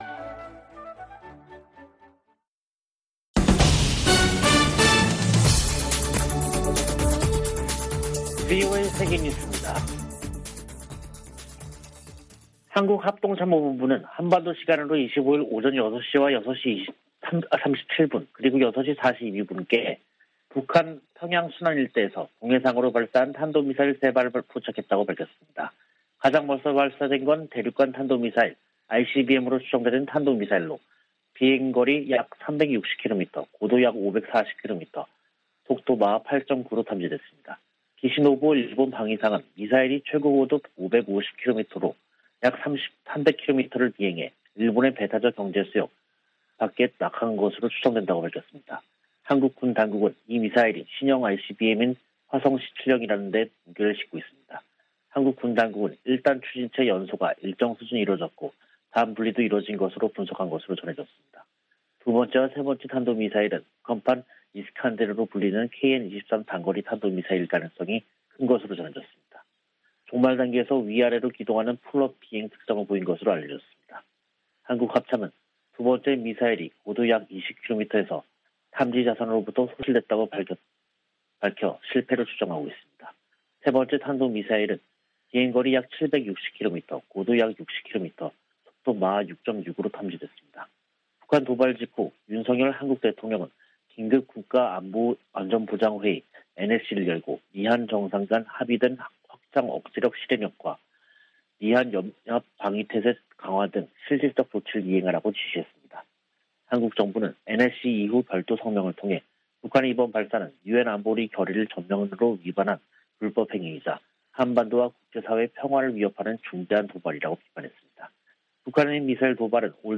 VOA 한국어 간판 뉴스 프로그램 '뉴스 투데이', 2022년 5월 25일 3부 방송입니다. 북한이 ICBM을 포함한 탄도미사일 3발을 동해상으로 발사했습니다.